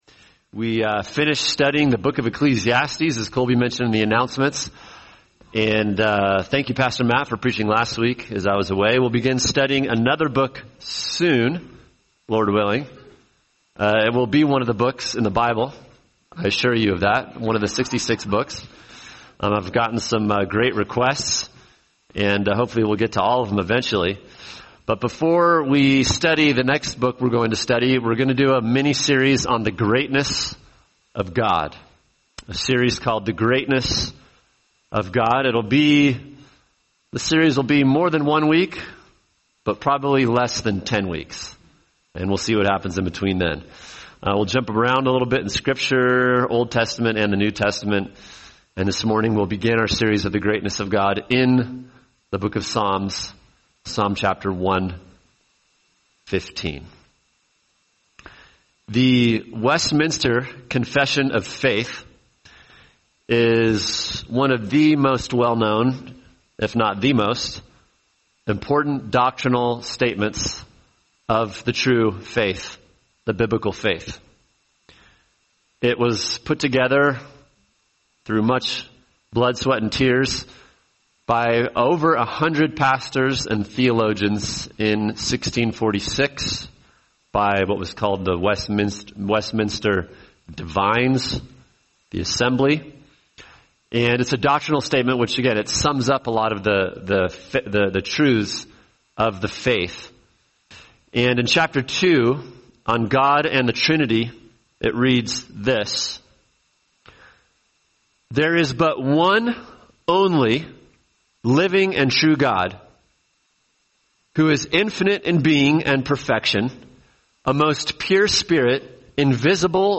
[sermon] Psalm 115 – The Greatness of God: The One True God | Cornerstone Church - Jackson Hole